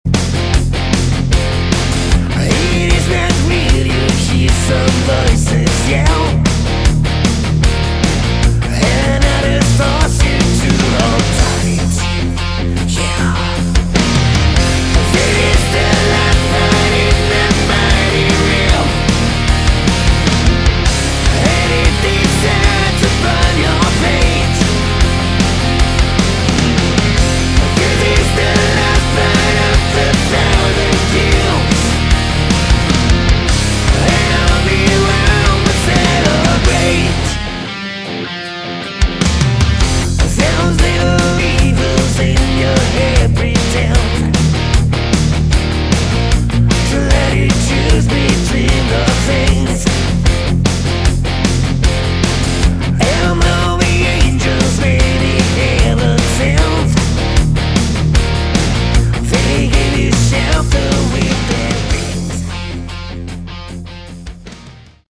Category: Hard Rock
vocals
guitar
bass
drums
Great guitar solos in every song